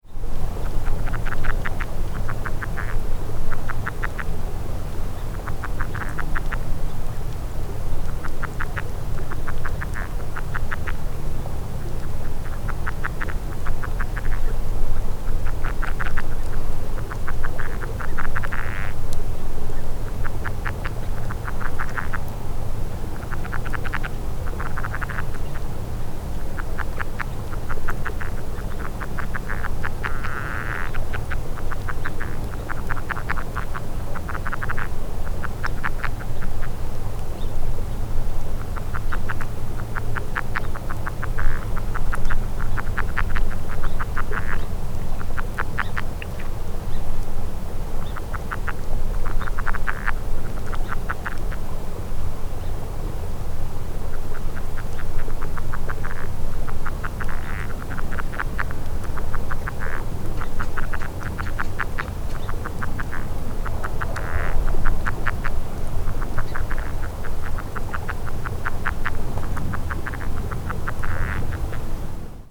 All sounds were recorded in the air (not underwater) at a close distance with a shotgun microphone.
Background sounds include: various birds calling and moving around in dry reeds, including ravens and song sparrows; flowing water; distant traffic; and distant farm equipment.
Sound  This is a 72 second continuous recording of a few frogs calling or chuckling rapidly - a faster and higher-pitched sound from the typical calls which can be heard above.